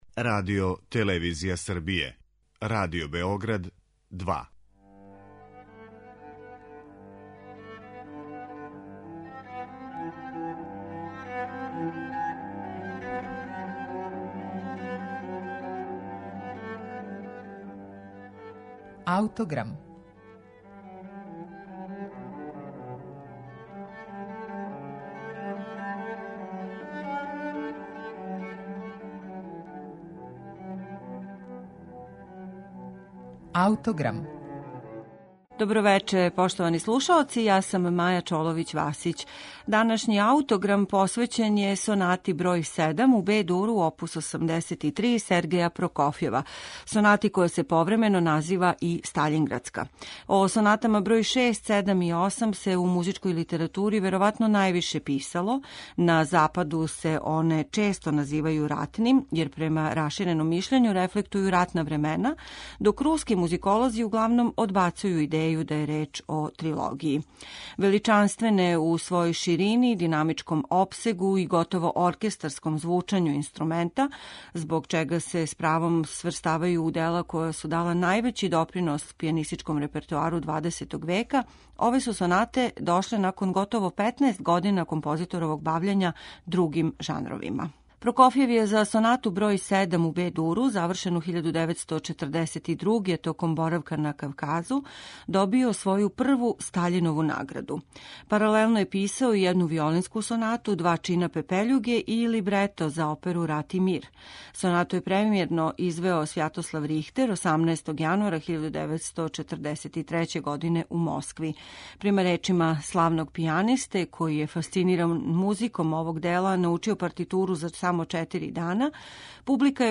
У данашњој емисији слушаћете снимак који је остварио пијаниста Јефим Бронфман.